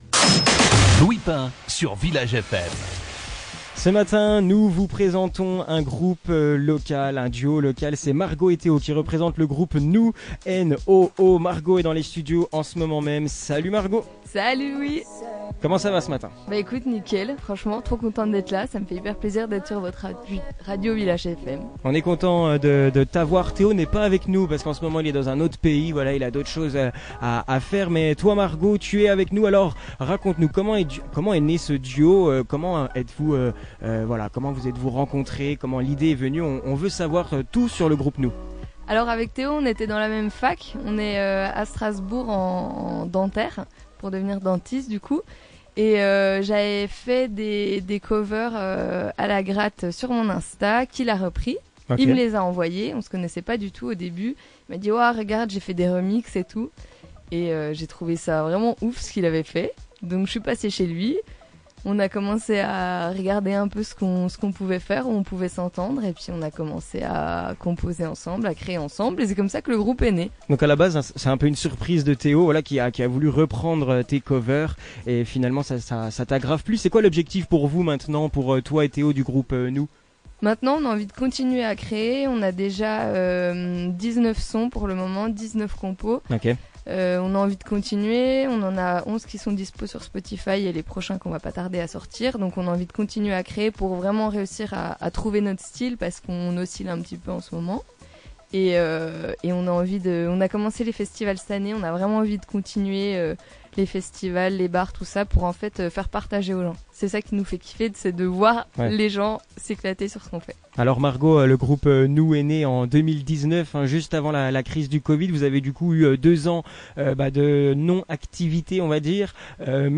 Deux univers musicaux qui se rencontrent, l’acoustique et l’électronique, pour former ce groupe electrochill...